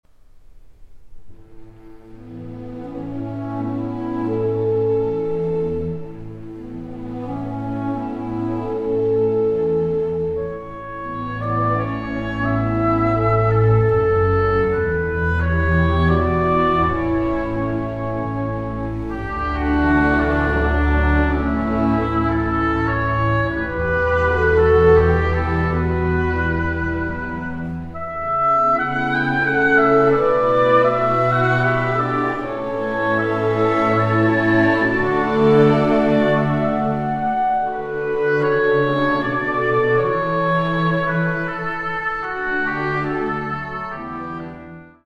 First Recordings made in the Presence of the Composer